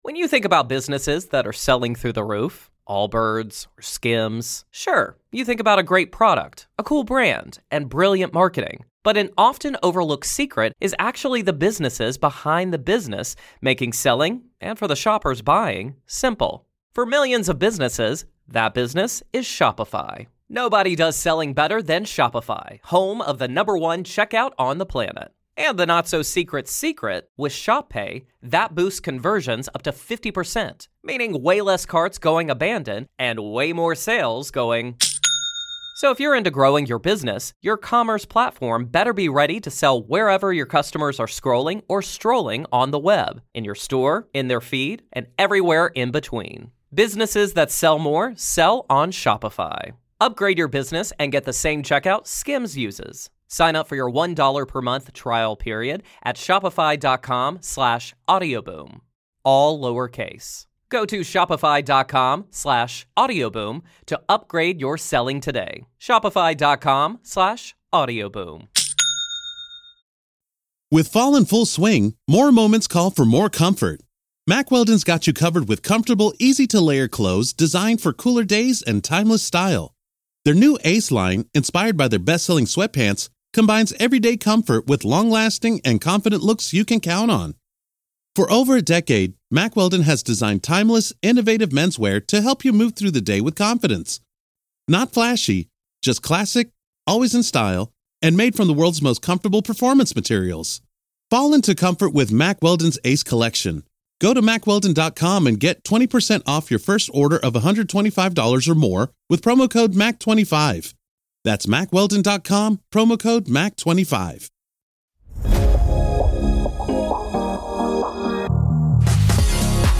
Esteban and Ollie talk car set ups and simulators, all while eating their pre-race lunch.